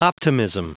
Prononciation du mot optimism en anglais (fichier audio)
Prononciation du mot : optimism